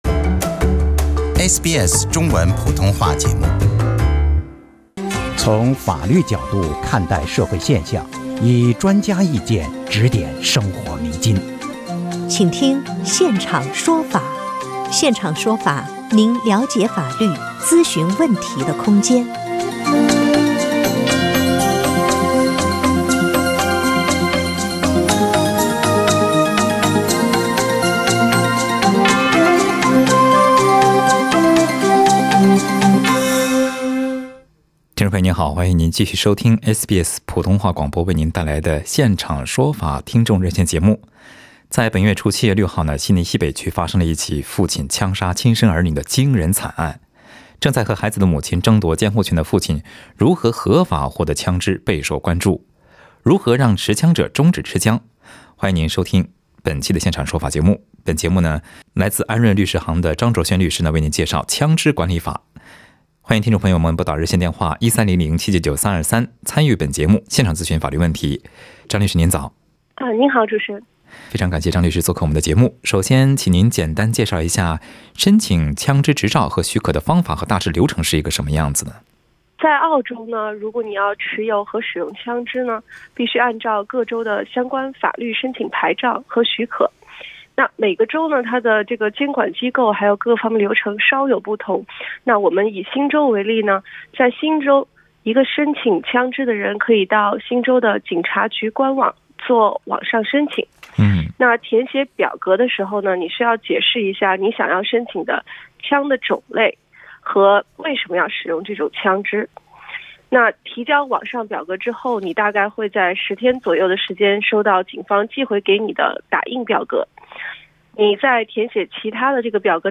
听众热线节目